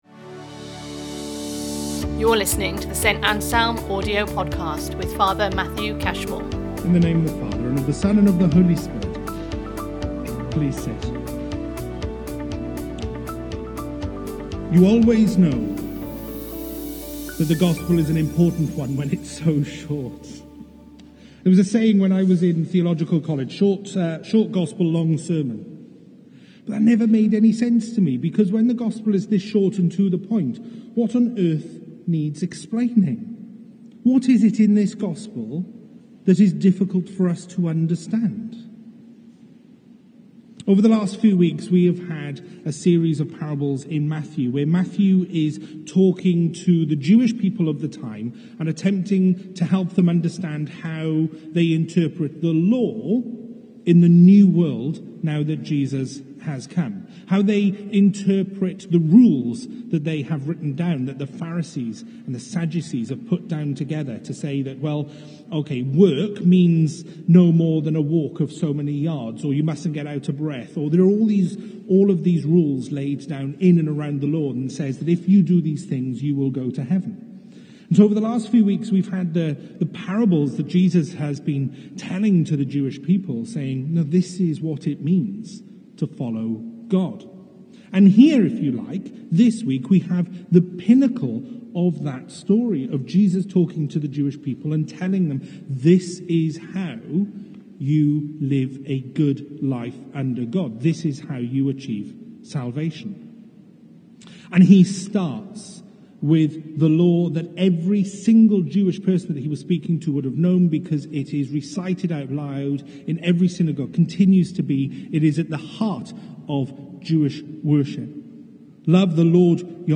Sunday Sermons